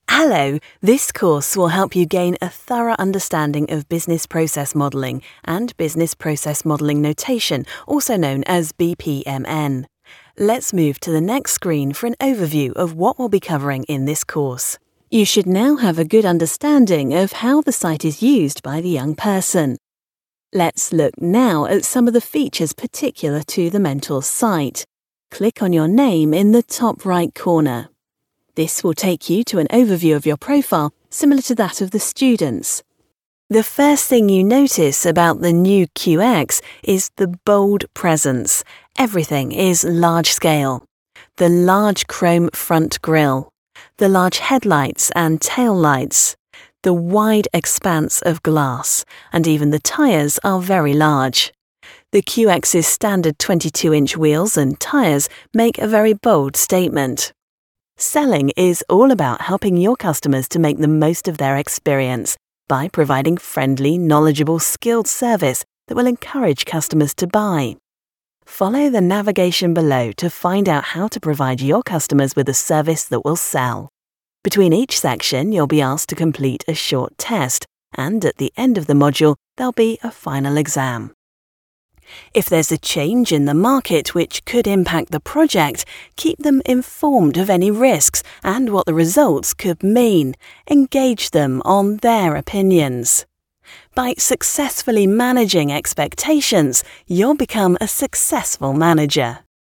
British female ISDN voice. Experienced, warm, natural, authentic, engaging and bright.
Sprechprobe: eLearning (Muttersprache):